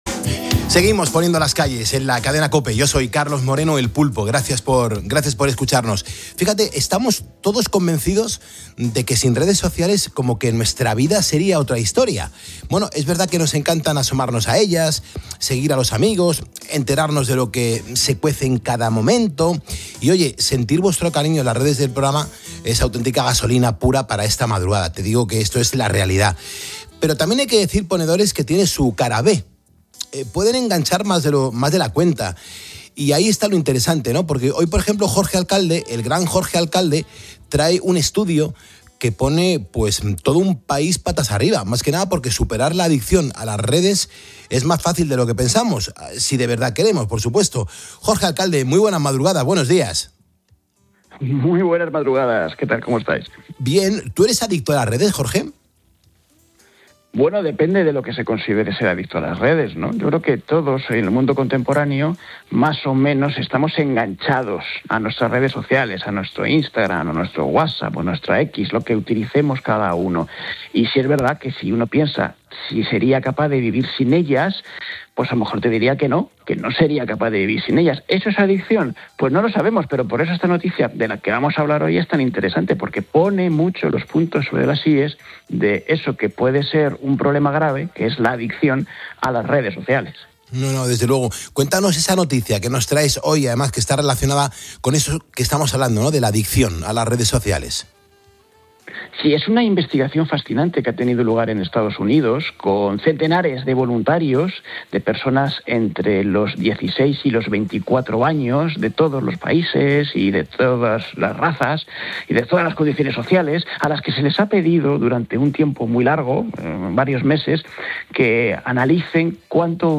charla con el divulgador científico